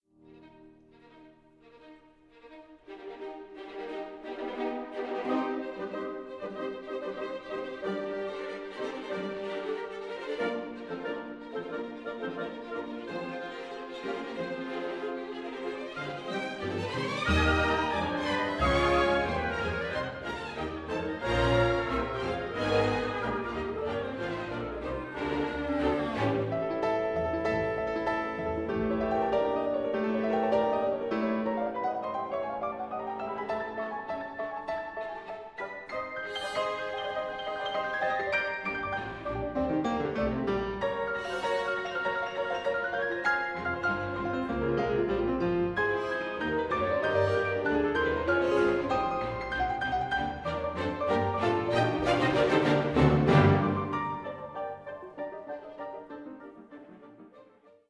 Multi-channel / Stereo